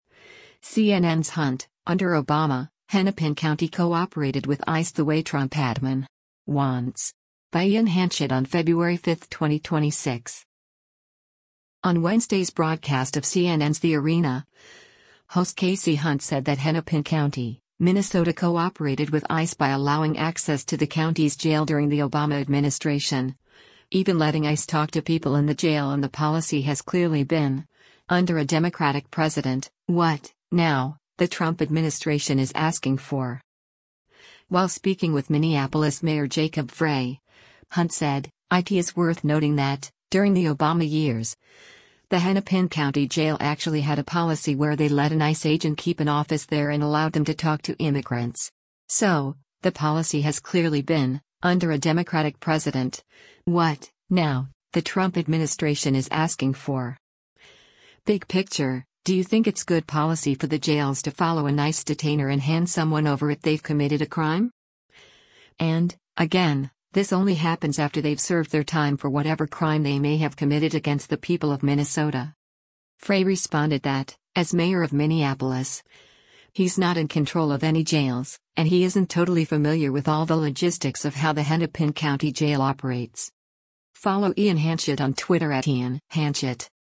On Wednesday’s broadcast of CNN’s “The Arena,” host Kasie Hunt said that Hennepin County, Minnesota cooperated with ICE by allowing access to the county’s jail during the Obama administration, even letting ICE talk to people in the jail and “the policy has clearly been, under a Democratic president, what, now, the Trump administration is asking for.”